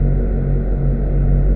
Index of /90_sSampleCDs/AKAI S6000 CD-ROM - Volume 6/Snth_FX/SYNTH_FX_3